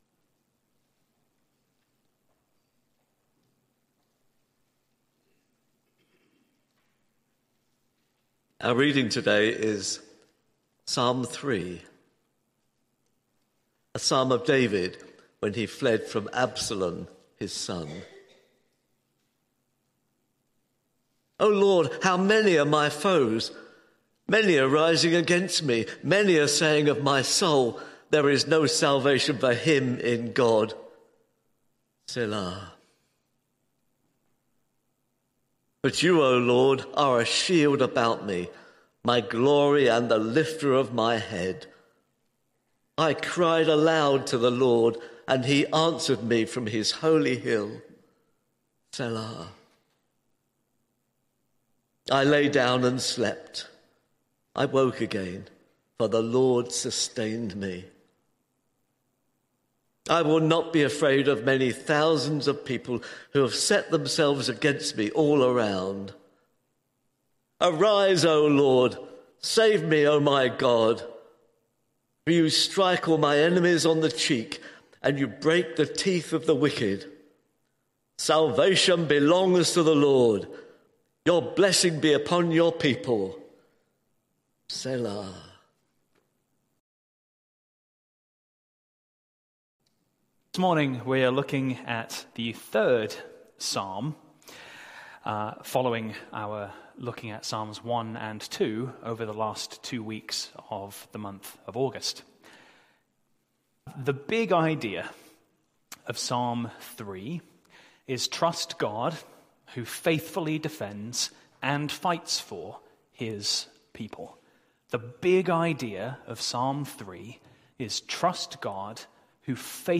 Sermon Series: The Psalms